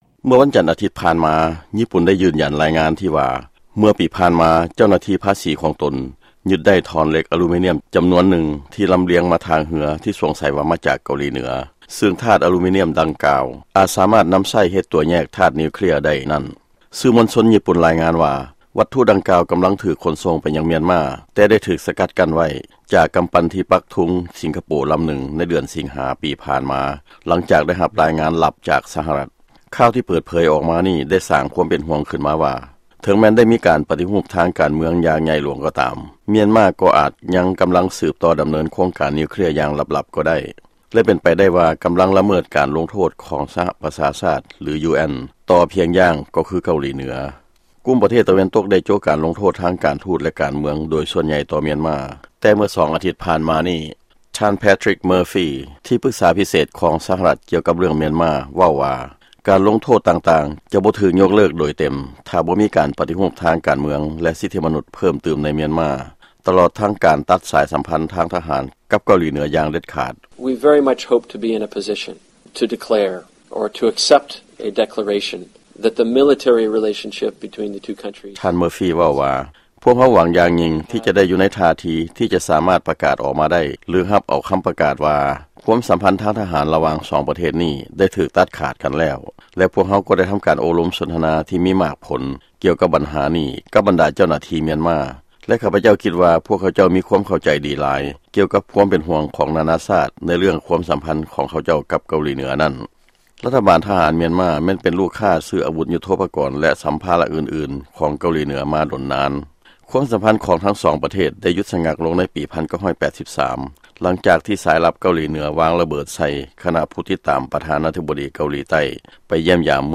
ຟັງລາຍງານກ່ຽວກັບມຽນມາ